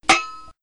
grenade_impact.wav